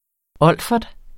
Udtale [ ˈʌlˀfʌd ]